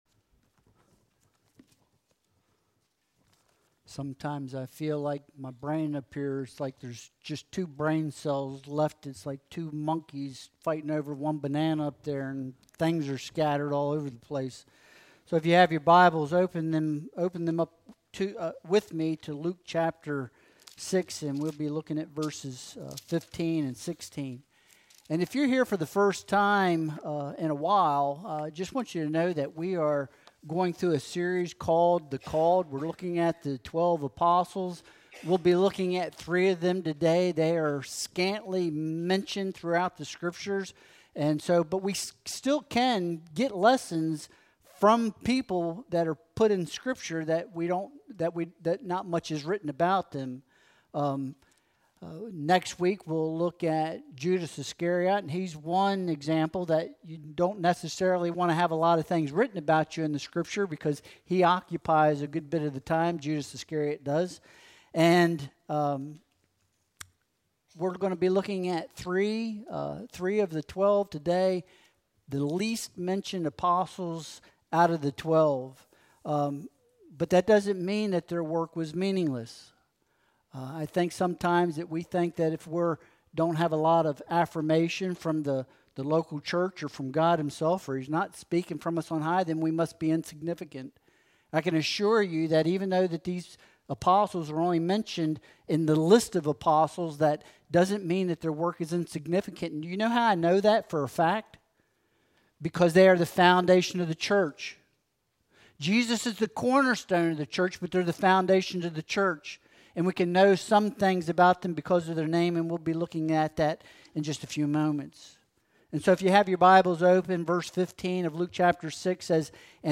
Luke 6.15-16 Service Type: Sunday Worship Service Download Files Bulletin « Elijah